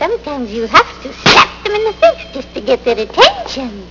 slap.wav